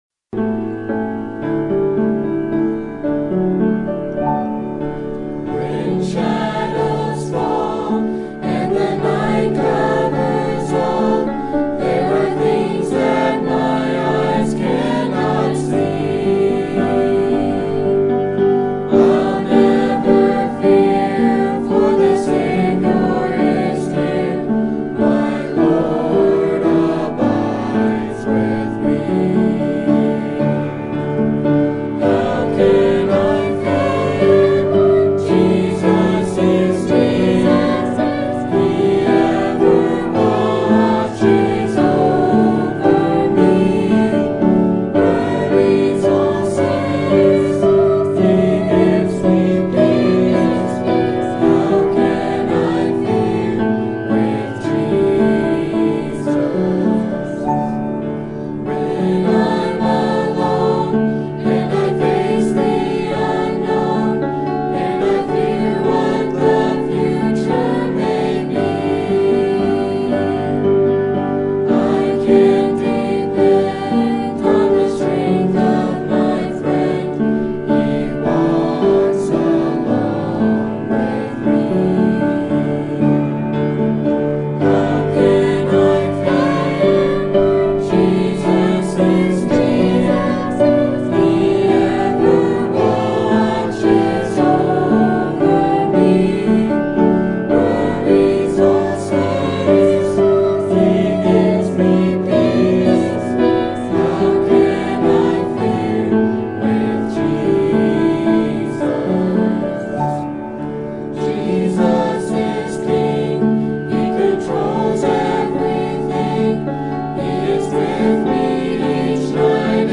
Mixed Group